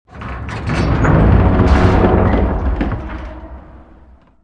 OrbitGearRetract.wav